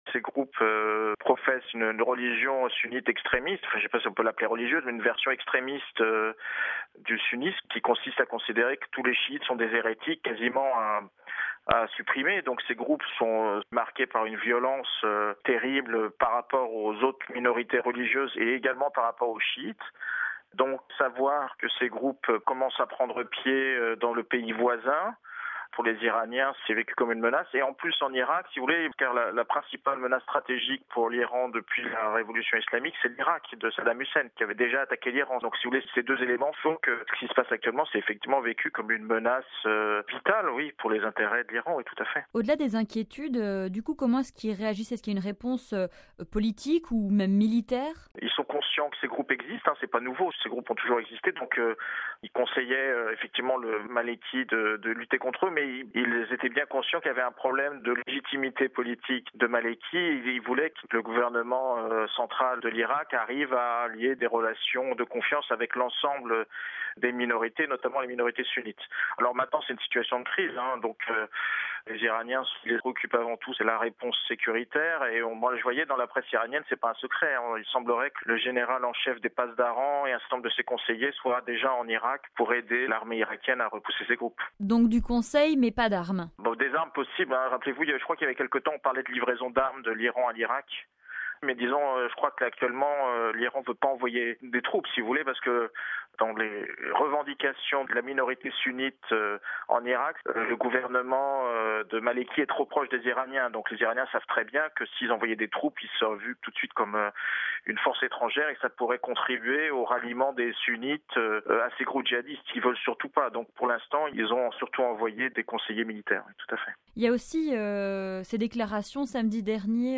(RV) Entretien - C'est l’une des conséquences inattendues du conflit irakien : Washington et Téhéran se sont rapprochés.